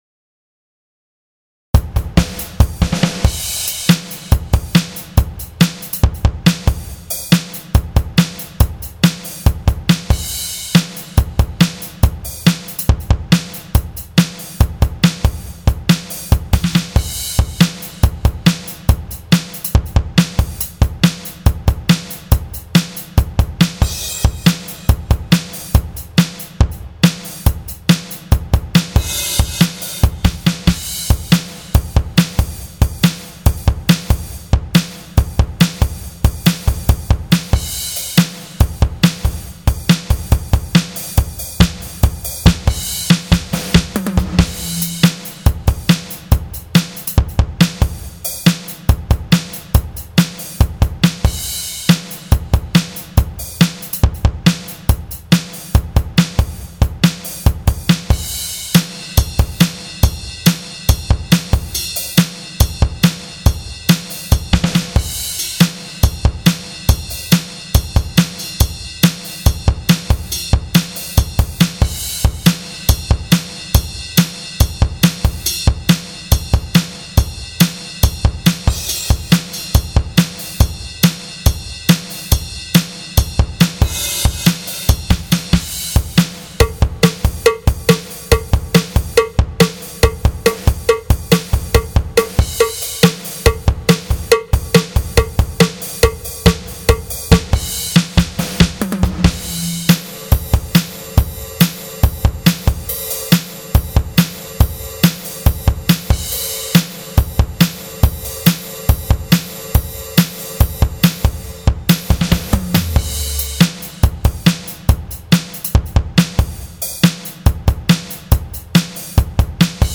Drum Grooves
Hard Rock 140.mp3